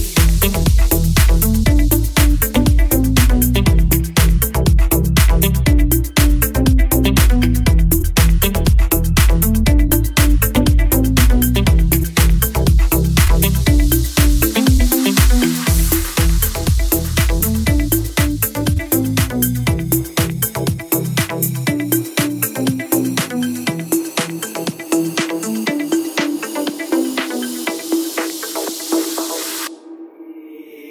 • Качество: 320, Stereo
remix
deep house
без слов
nu disco
Indie Dance
Стиль: nu disco